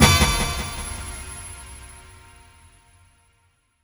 horn_chime.wav